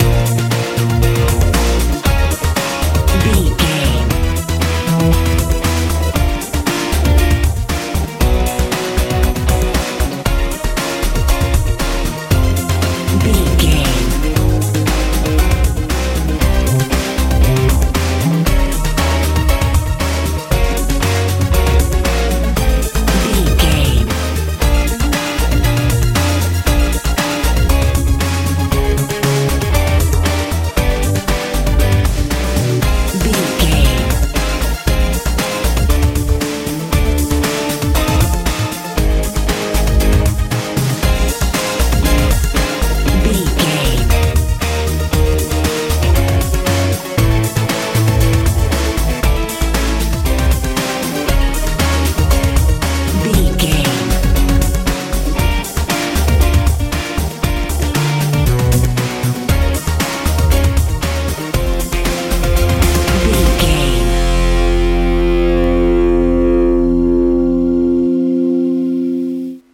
modern dance feel
Ionian/Major
Fast
energetic
searching
electric guitar
bass guitar
drums
synthesiser
80s
90s
suspense